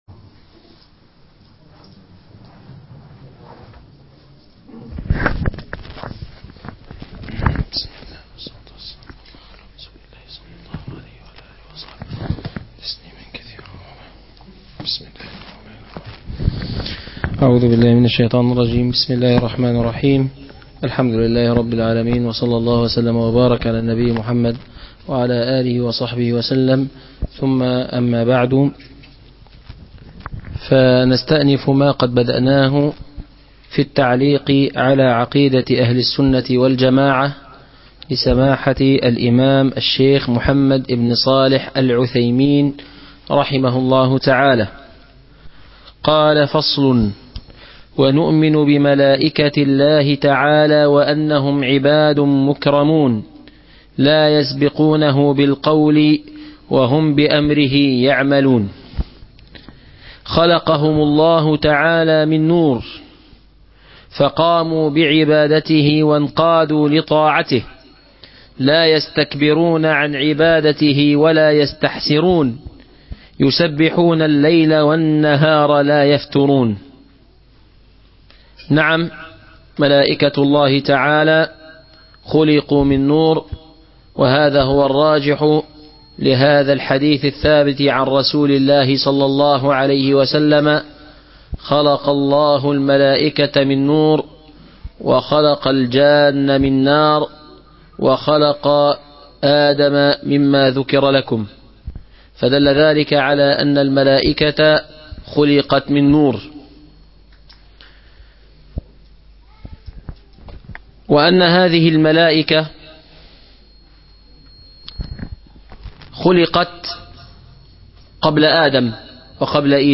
الدرس الثاني من عقيدة أهل السنه والجماعه